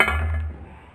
用ipod touch第4次录音.经过加工.声音效果.来自塑料、玻璃、木头制成的物体...